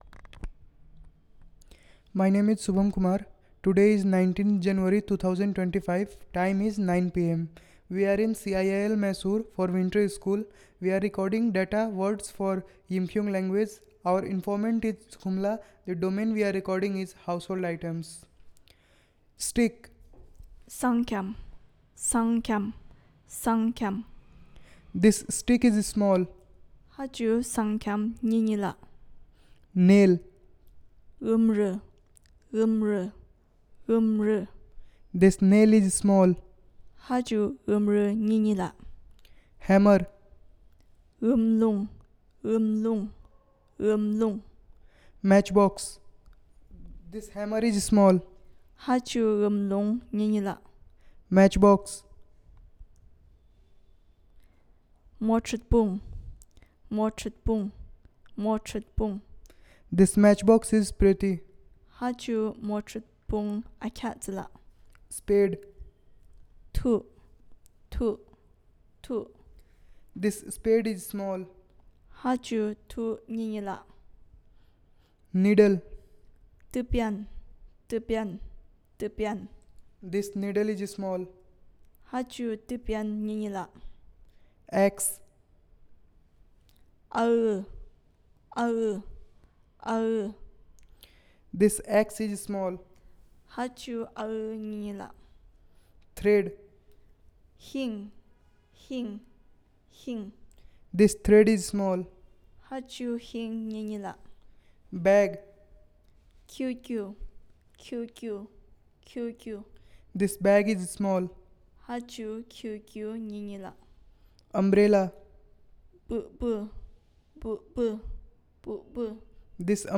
Elicitation of words related to Household Items